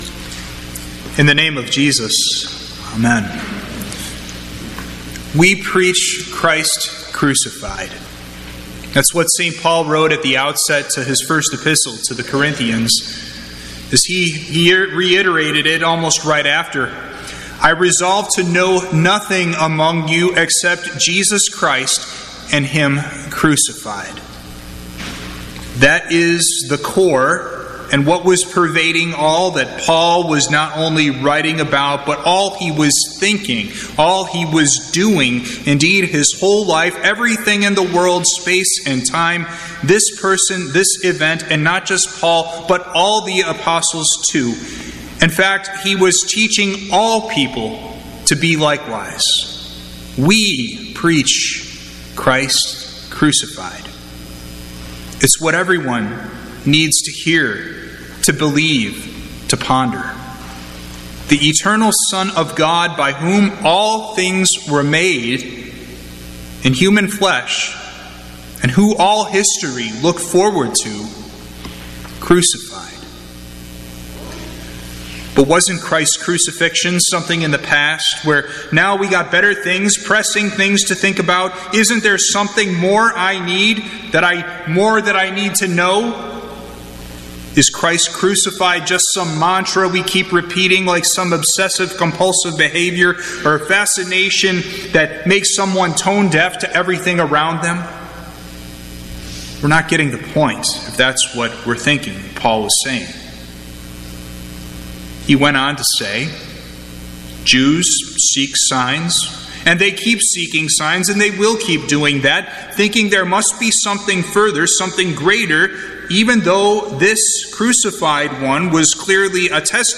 Sermon
Good Friday – Holy Cross Lutheran Church